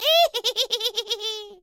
Здесь вы найдете жуткие стоны, таинственные шорохи, зловещий смех и другие эффекты, которые сделают ваш праздник по-настоящему пугающим.
Смешной смех ведьмы